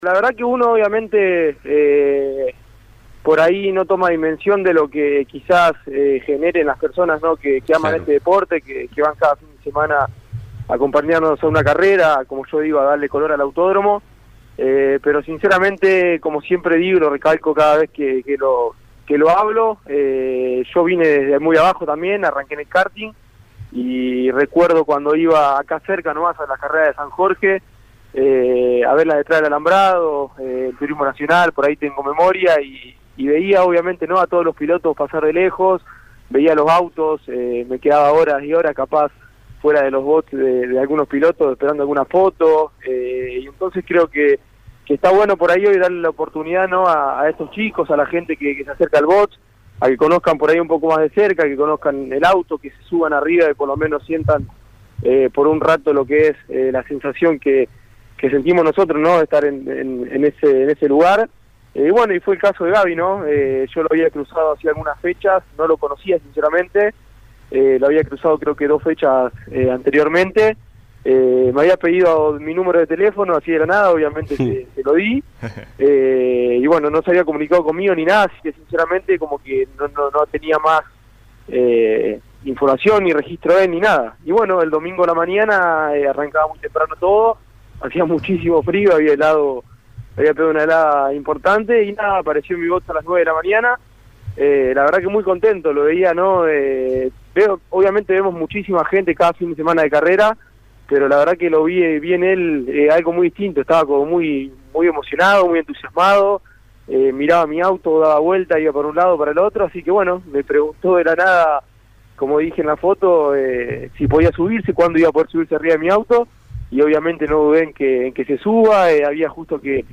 Por tal motivo, nos pusimos en contacto con uno de los protagonistas y esto manifestó en El Arranque (lunes a viernes a las 10hs por Campeones Radio).